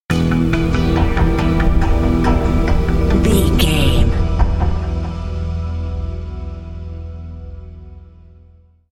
Music
Aeolian/Minor
synthesiser
ominous
dark
suspense
haunting
creepy
spooky